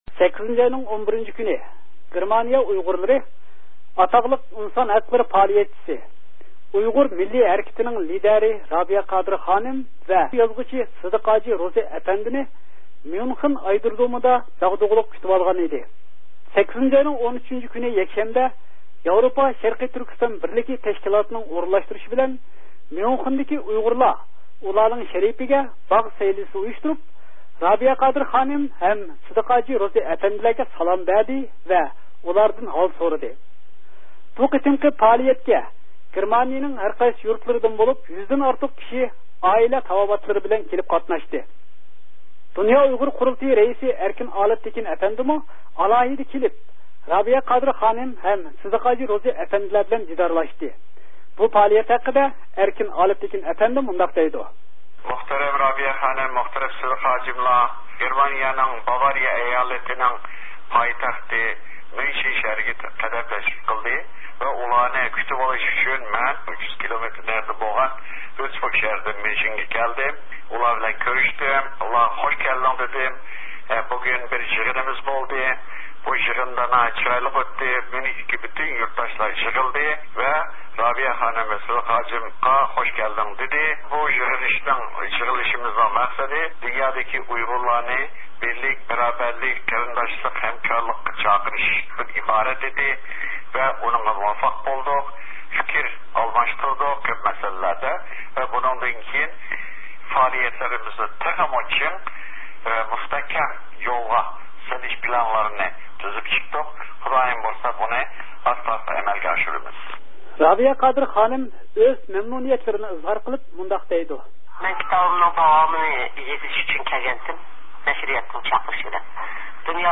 8 – ئاينىڭ 11 – كۈنى، گېرمانىيە ئۇيغۇرلىرى ئاتاقلىق ئىنسان ھەقلىرى پائالىيەتچىسى، ئۇيغۇر مىللىي ھەرىكىتىنىڭ رەھبىرى رابىيە قادىر خانىم ۋە مەشھۇر يازغۇچى سىدىق ھاجى روزى ئەپەندىنى ميۇنخېن ئايرودرومىدا داغدۇغىلىق كۈتۈۋالغان ئىدى. 8 – ئاينىڭ 13 – كۈنى يەكشەنبە، ياۋروپا شەرقىي تۈركىستان بىرلىكى تەشكىلاتىنىڭ ئورۇنلاشتۇرىشى بىلەن، ميۇنخېندىكى ئۇيغۇرلار ئۇلارنىڭ شەرىپىگە باغ سەيلىسى ئۇيۇشتۇرۇپ، رابىيە قادىر خانىم ھەم سىدىق ھاجى روزى ئەپەندىلەرگە سالام بەردى ۋە ئۇلاردىن ھال سورىدى.